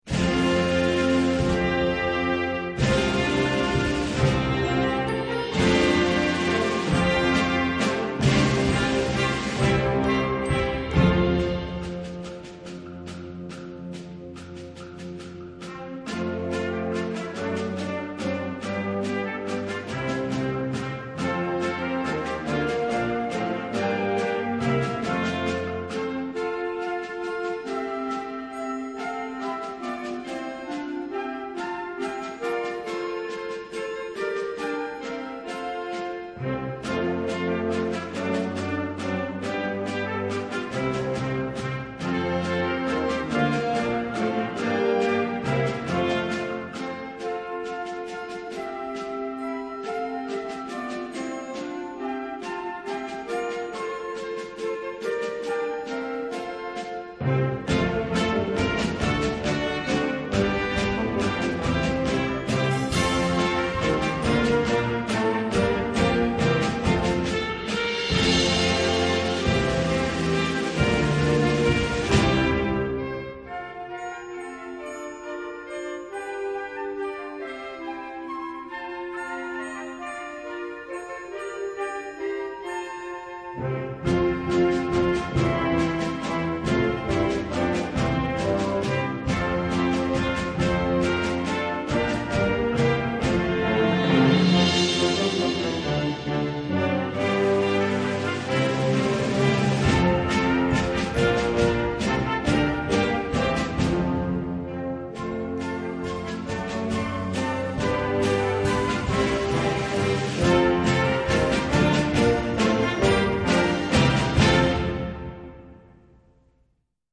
Gattung: Weihnachtsmusik
Besetzung: Blasorchester